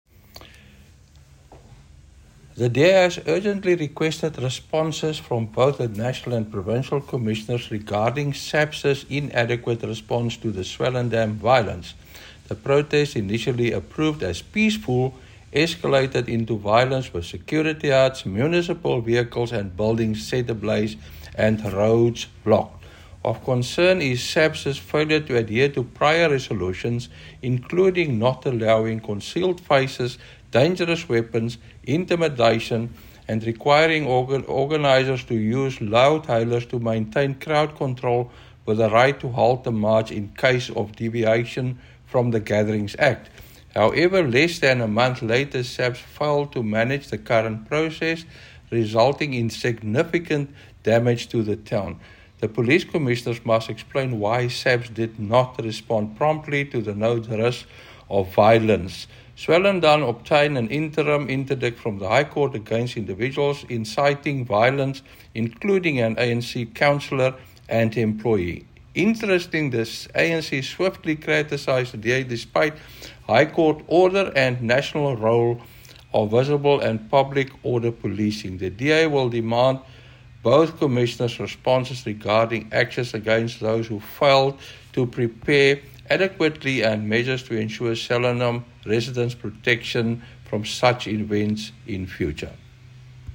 soundbite by Okkie Terblanche MP.